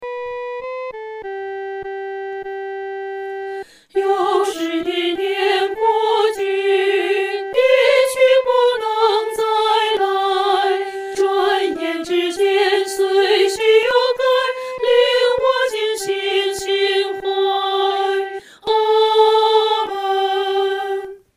合唱
女高
诗班在练习这首诗歌时，要清楚这首诗歌音乐表情是如歌地。